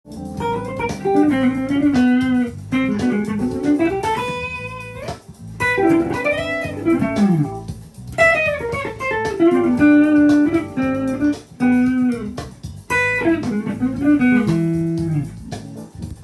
以上のフレーズを使いAm７上でソロを弾くとこんな感じになります。